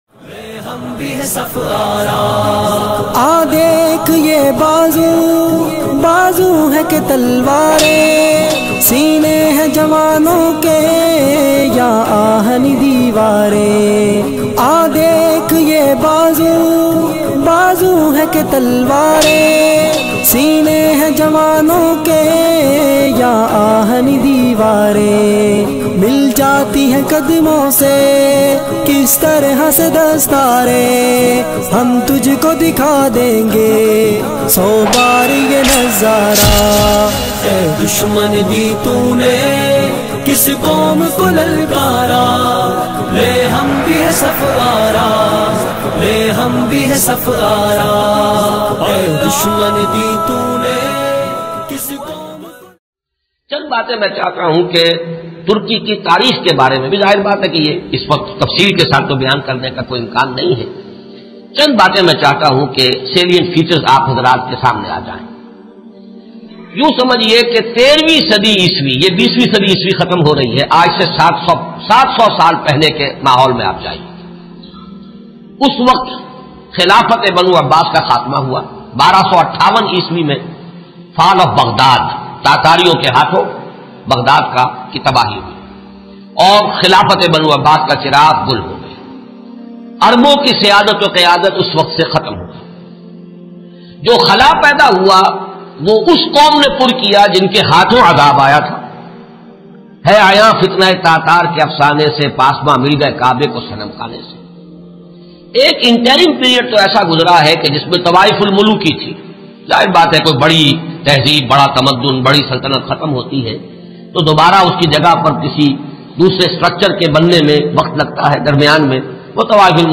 Ertugrul Ghazi History Bayan MP3 Download Dr Israr Ahmed